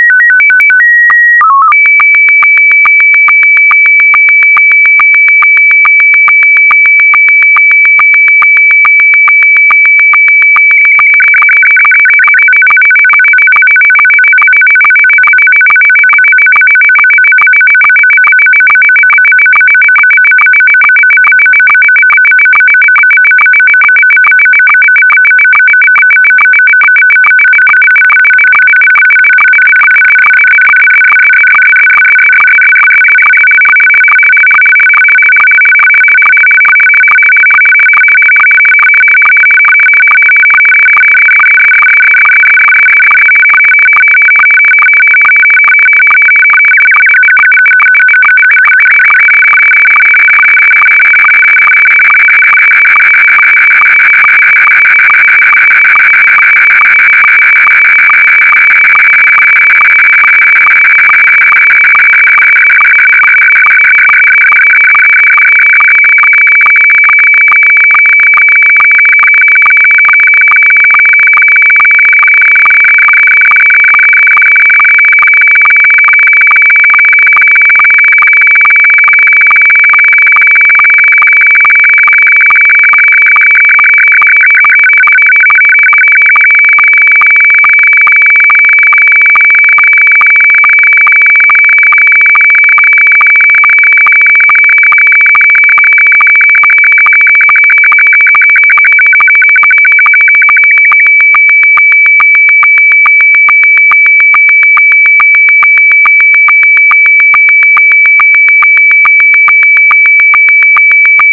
Aqui te dejo un audio con una imagen SSTV codificada en modo Robot36.
En el espectrograma verás bloques pausados y ordenados, no es solo ruido.
• Un tono inicial estable (líder) y luego bloques ordenados en el espectrograma.
robot36-demo.wav